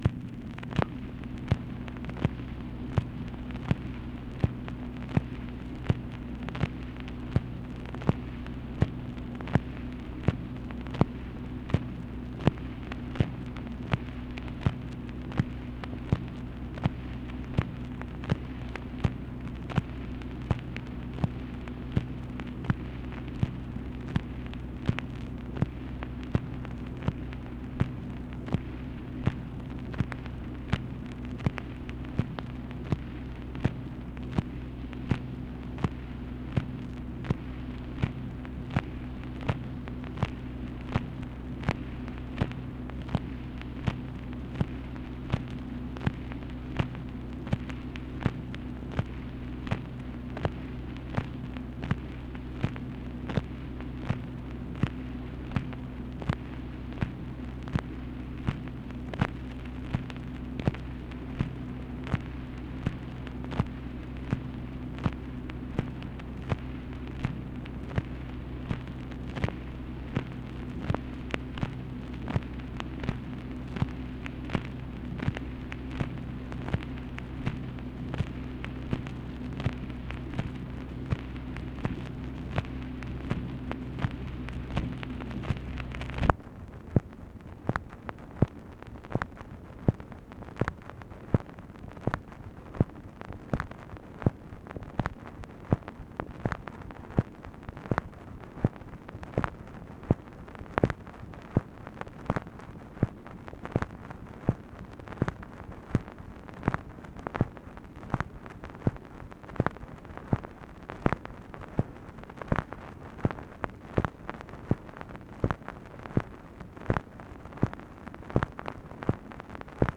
MACHINE NOISE, December 30, 1967
Secret White House Tapes | Lyndon B. Johnson Presidency